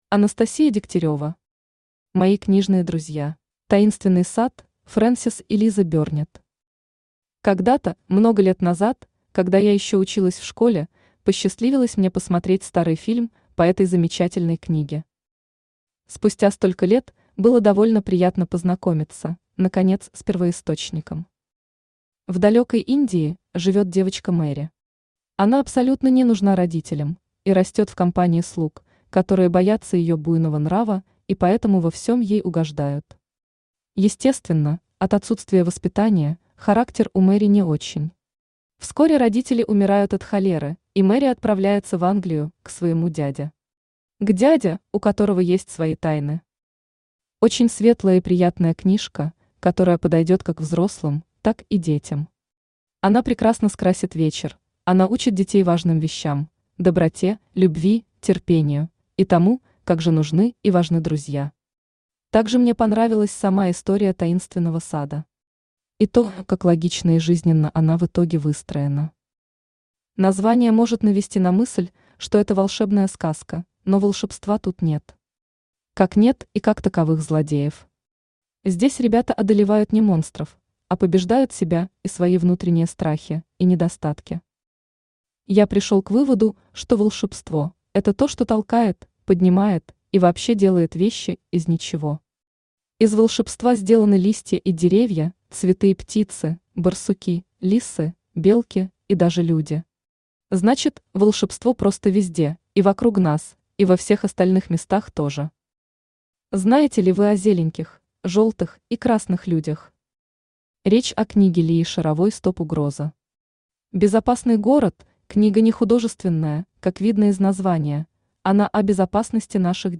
Аудиокнига Мои книжные друзья | Библиотека аудиокниг
Читает аудиокнигу Авточтец ЛитРес.